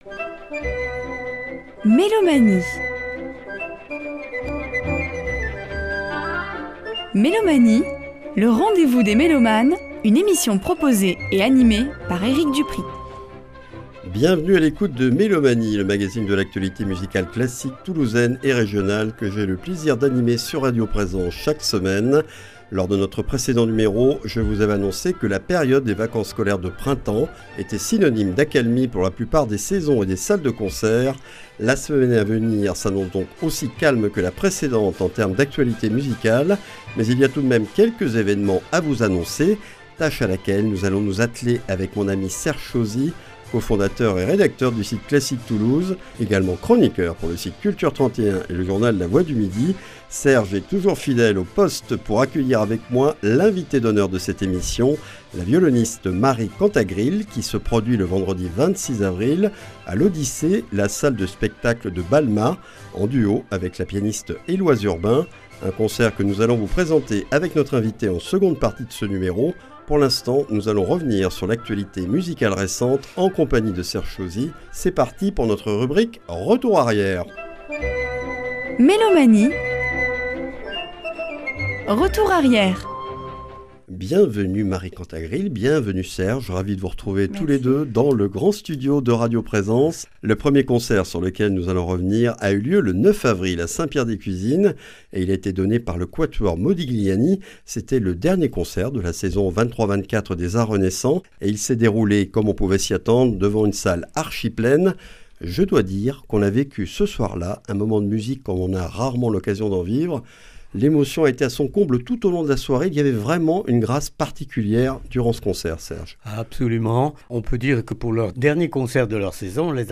Mélomanie(s) reçoit la violoniste